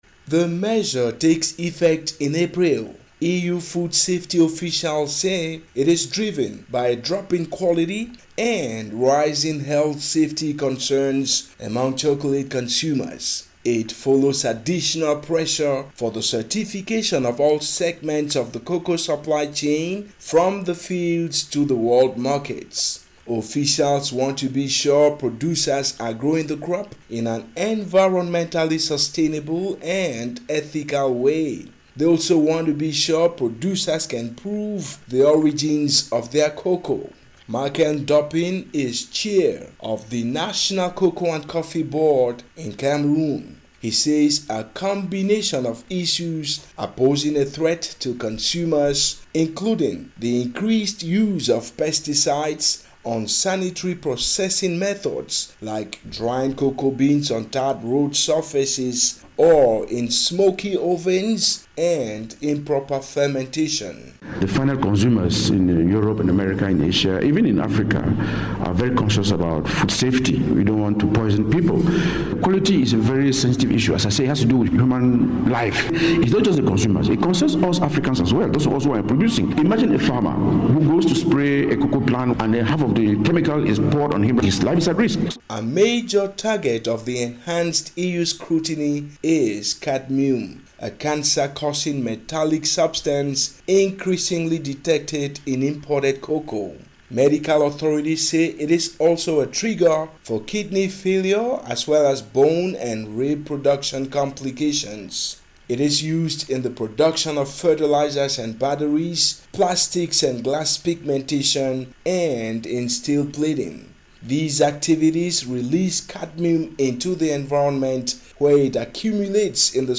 Listen to report on new cocoa regulations